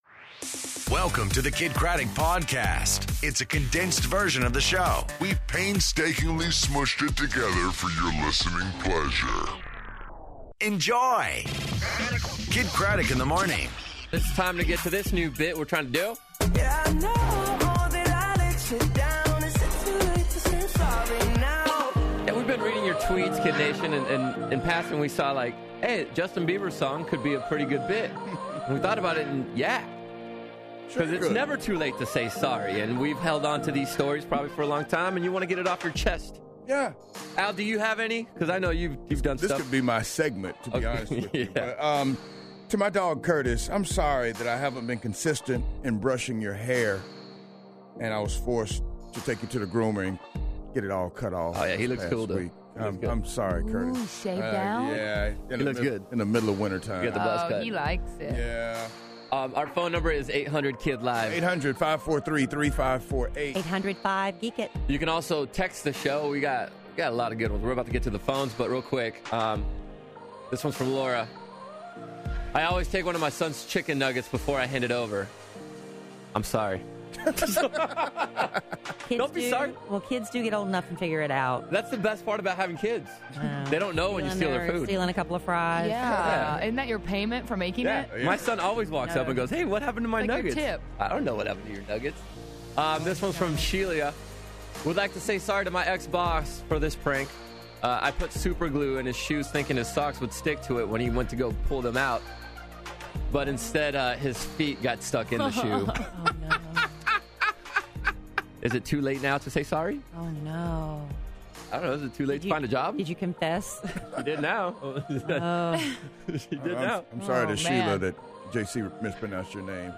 Interviews Chloe Grace-Moretz